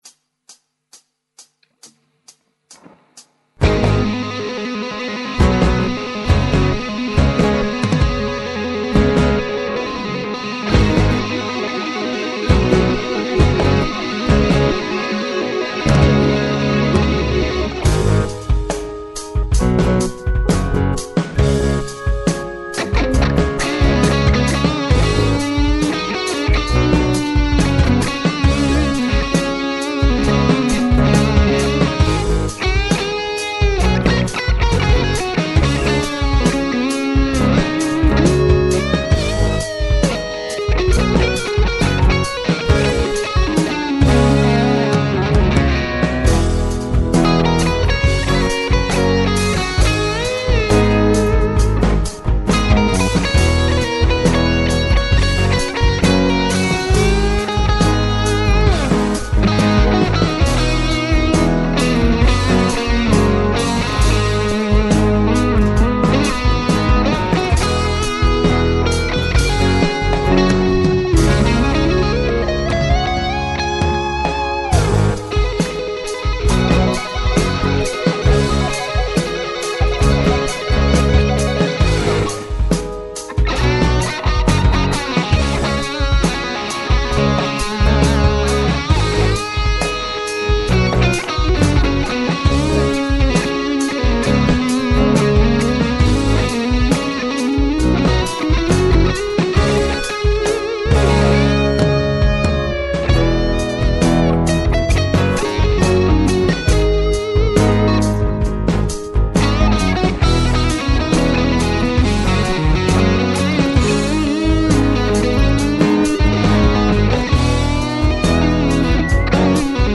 ich höre gerade: das ist einen Halbton tiefer????
: Dazwischen etwas Zeit für einen Aussenjam.
So ein Highgain Sound klingt schon sehr künstlich/digital mit meinem GuitarPort - Sounds mit weniger Gain sind IMHO da besser (vorallem wenn man am VOL-Poti etwas abdreht).
: Jedenfalls - Strat von der Wand und - keine Gefangenen machen ...